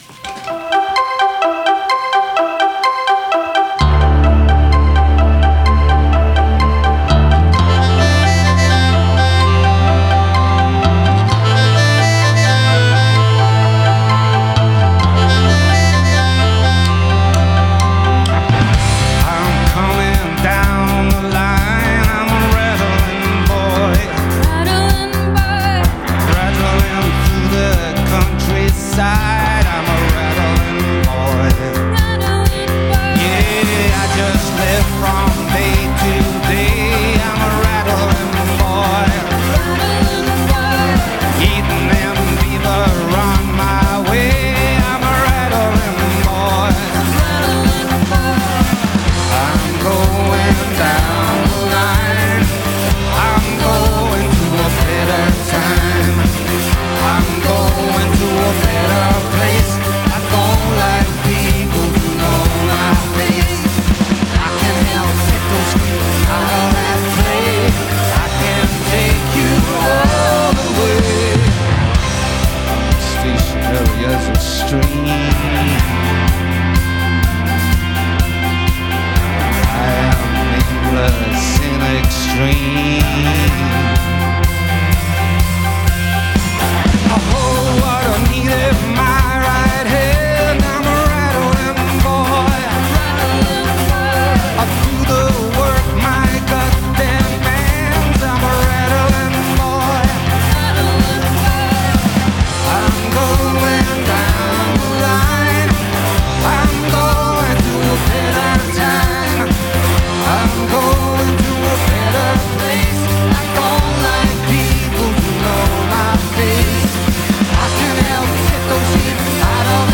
vocals
guitar
bass guitar
keyboards and backing vocals
drums